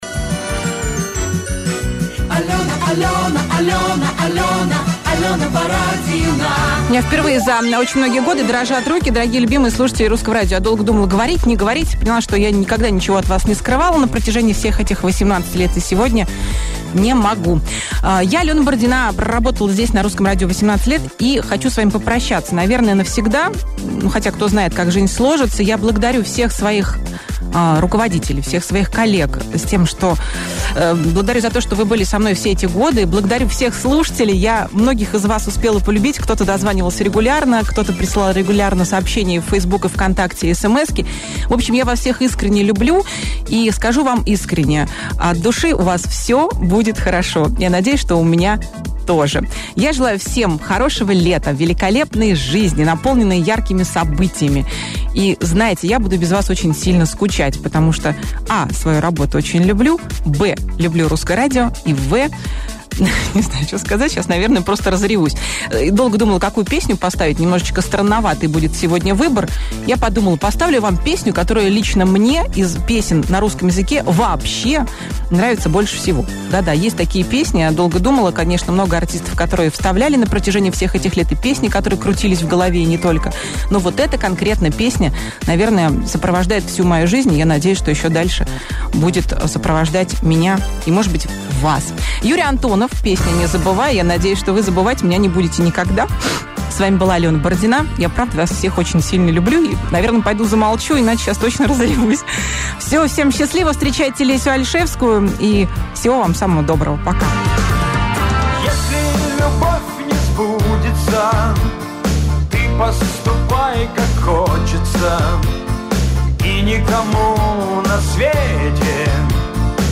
Об этом популярная радиоведущая объявила в прямом эфире.
Формат: National CHR
Ведущая: Алена Бородина